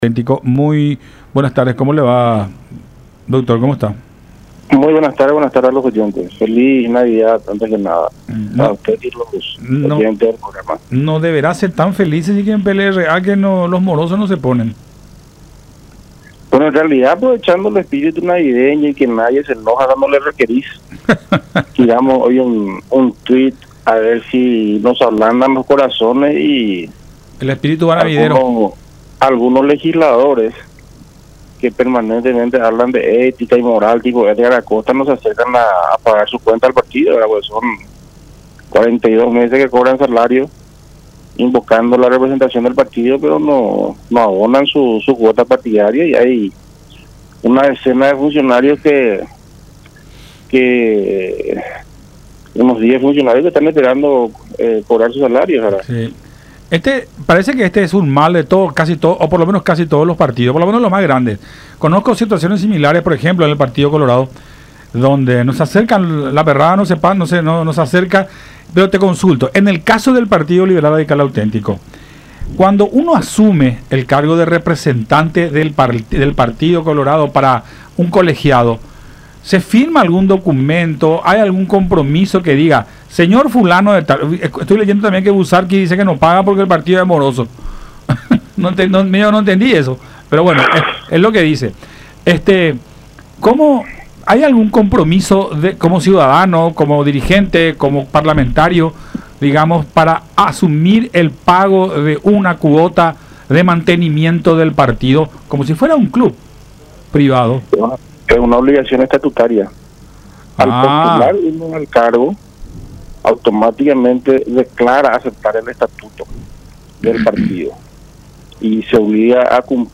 en diálogo con Buenas Tardes La Unión.